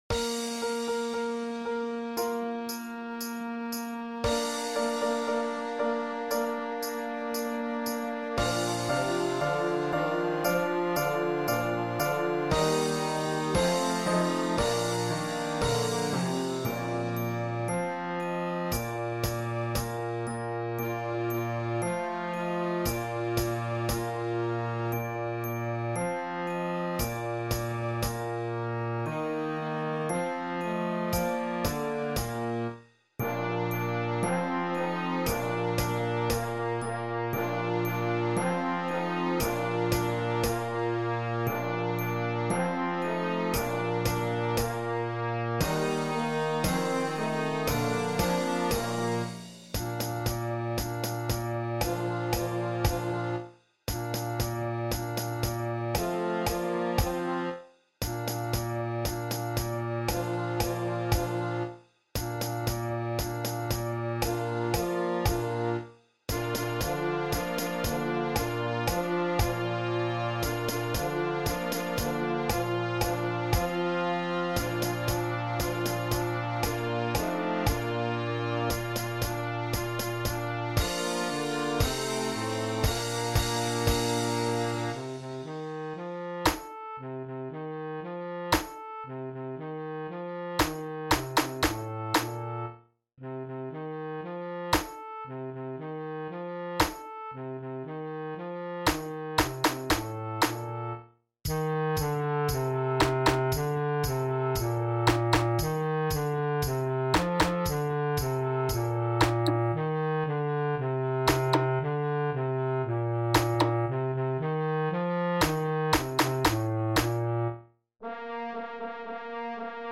Beginner Concert Band